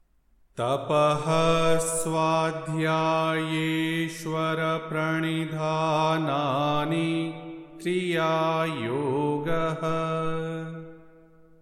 Yoga Sutra 2.1 | Tapaḥ-svādhyāyeśhvara-pra...| Chant Sutra 2.1